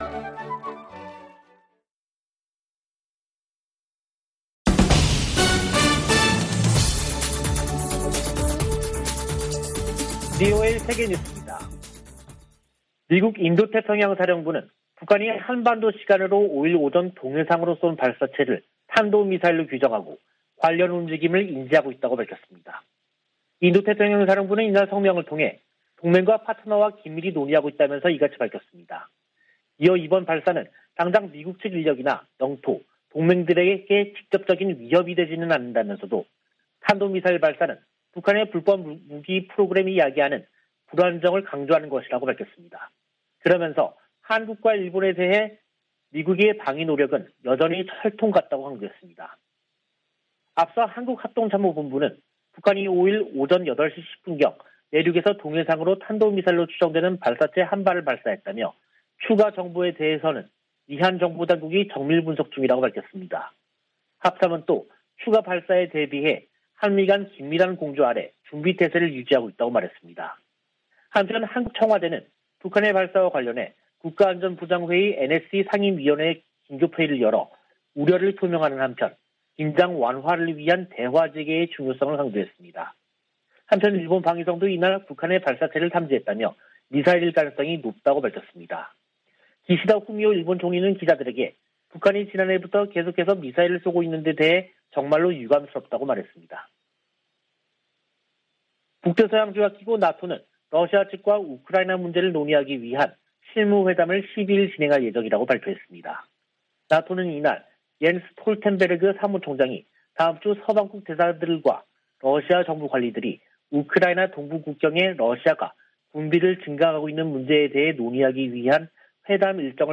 VOA 한국어 간판 뉴스 프로그램 '뉴스 투데이', 2022년 1월 5일 3부 방송입니다. 북한이 동해상으로 탄도미사일로 추정되는 발사체를 쐈습니다.